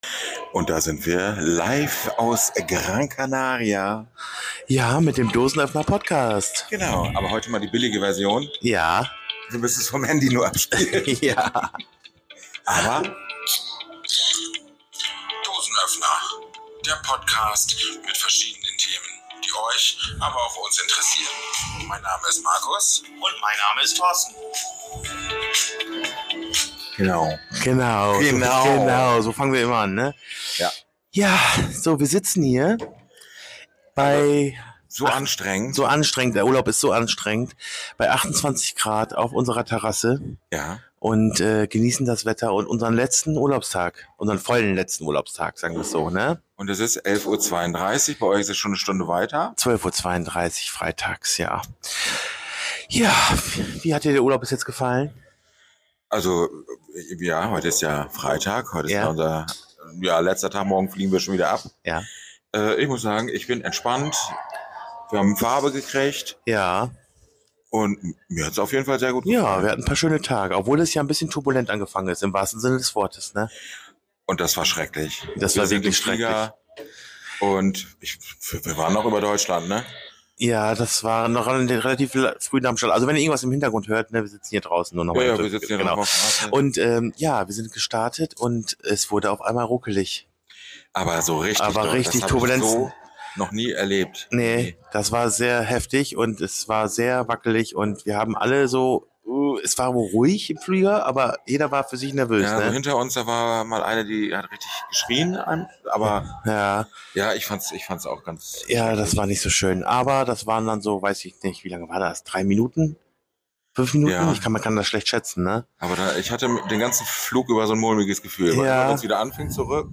Diesmal live von der Hotelterasse auf Gran Canaria.